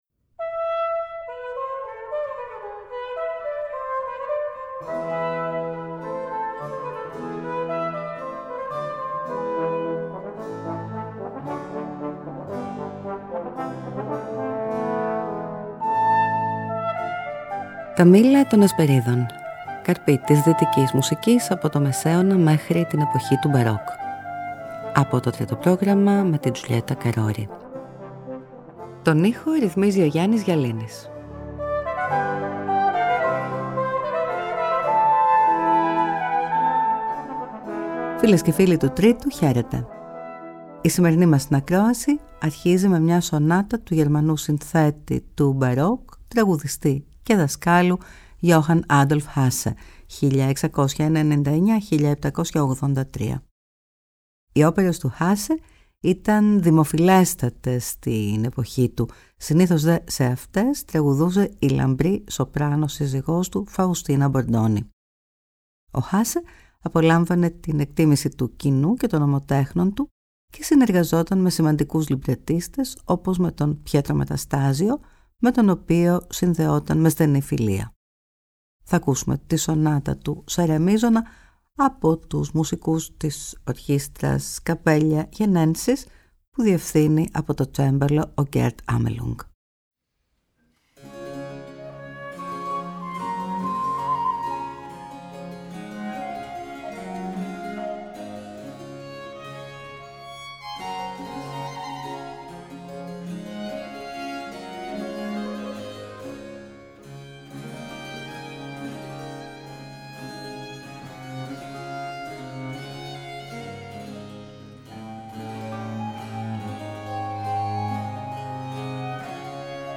Johann Adolph Hasse Σονάτα σε Ρε Μείζονα
Johann Cristoph Friedrich Bach ΠυγμαλίωνWilliam Young ενόργανη μουσική